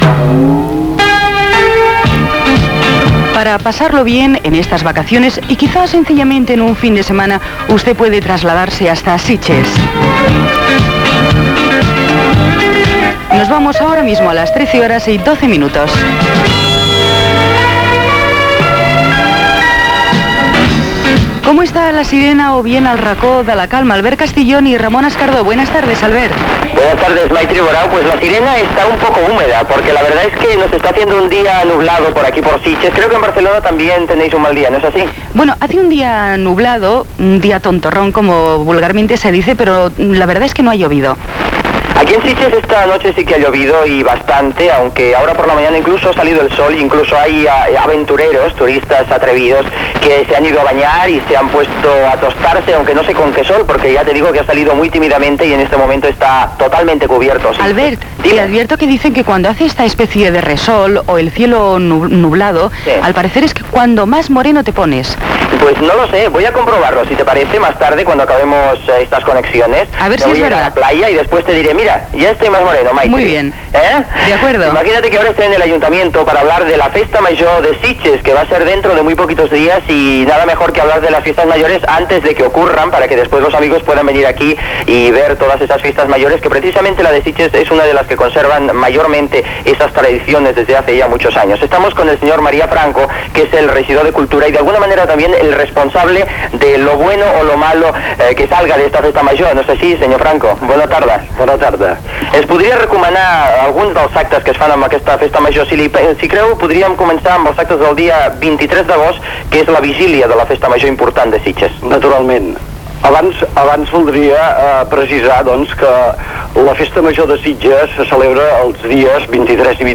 Hora, connexió amb Sitges per parlar de la seva festa major amb el regidor de cultura Marià Franco
Entreteniment